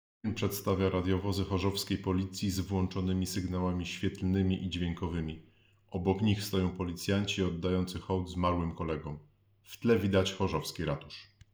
Nagranie audio Audiodeskrypcja_do_filmu.mp3